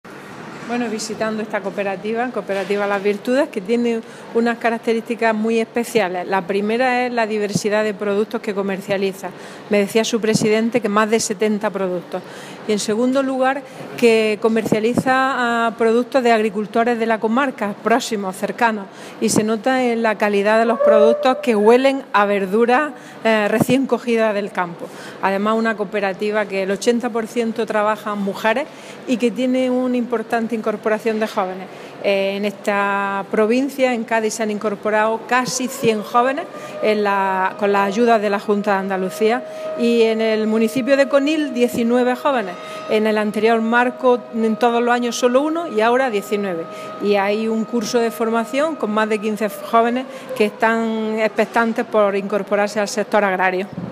Declaraciones de la consejera en la visita a la cooperativa Nuestra Señora de las Virtudes de Conil de la Frontera (Cádiz)